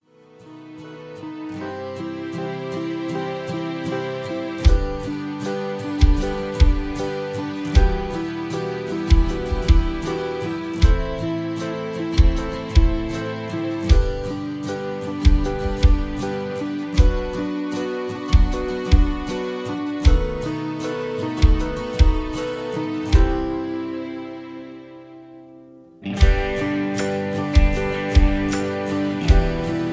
(Key-G)
Just Plain & Simply "GREAT MUSIC" (No Lyrics).
mp3 backing tracks